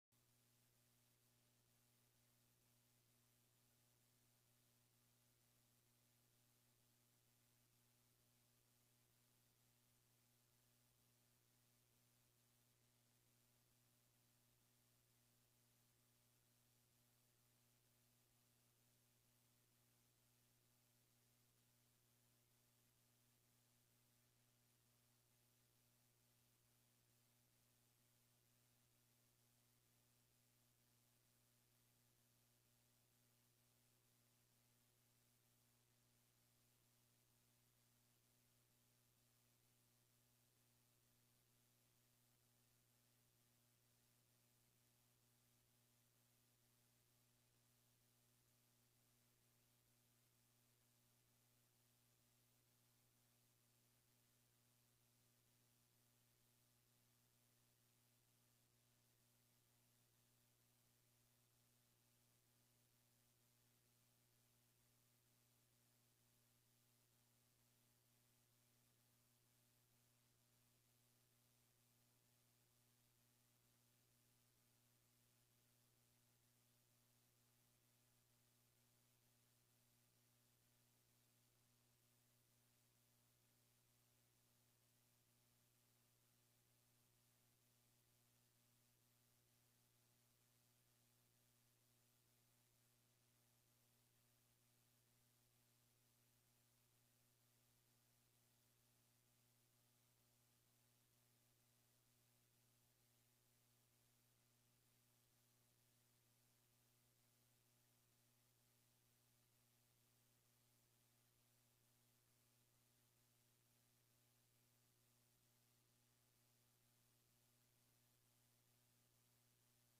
Este episodio forma parte de una serie de capítulos donde entrevistamos a varios candidatos a puestos de elección popular y estos nos presentan sus propuestas para ser dialogadas en vivo.